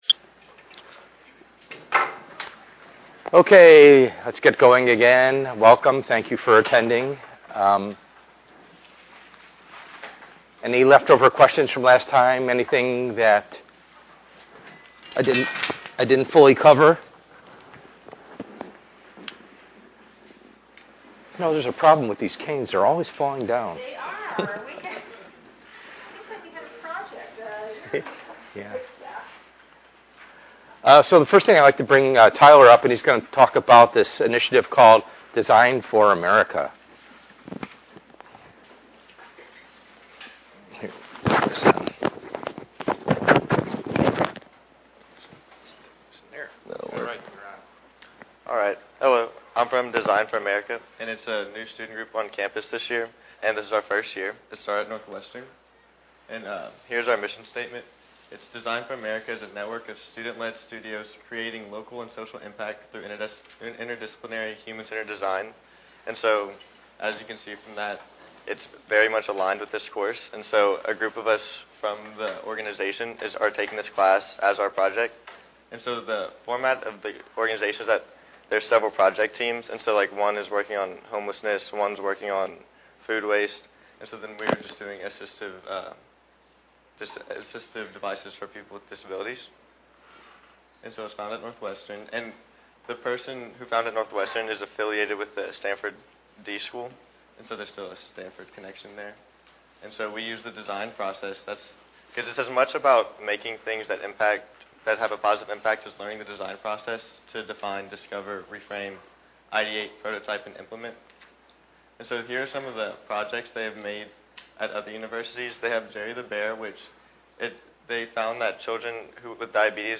ENGR110/210: Perspectives in Assistive Technology - Lecture 02b